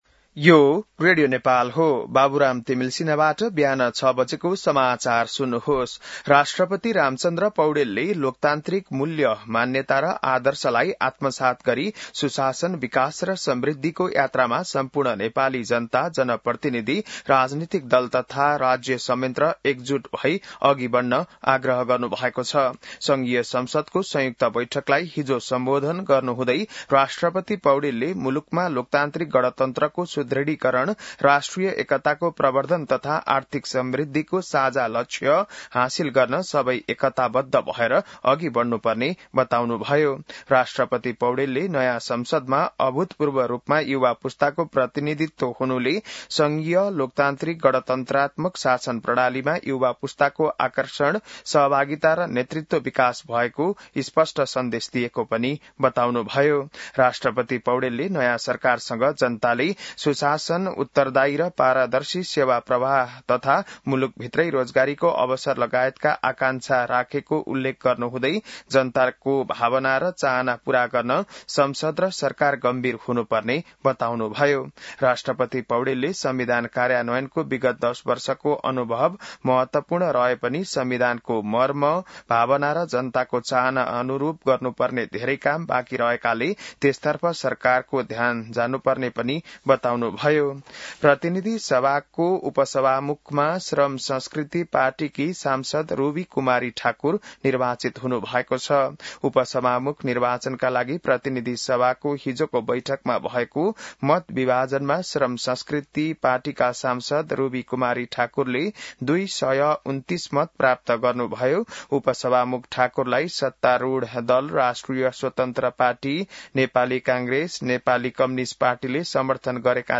बिहान ६ बजेको नेपाली समाचार : २८ चैत , २०८२